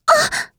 s026_Impact_Hit.wav